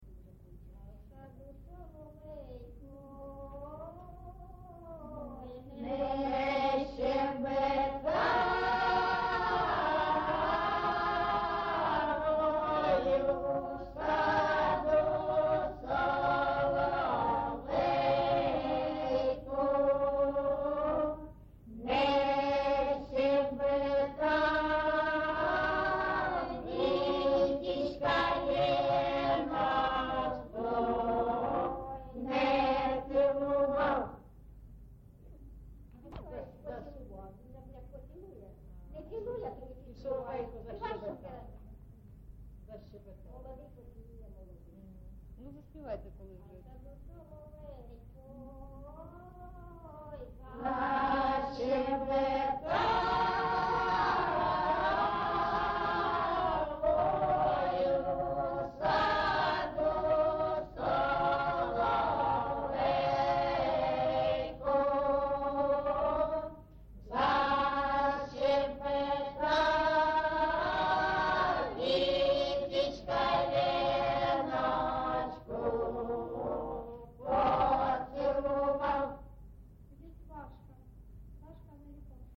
ЖанрВесільні
Місце записус. Маринівка, Шахтарський (Горлівський) район, Донецька обл., Україна, Слобожанщина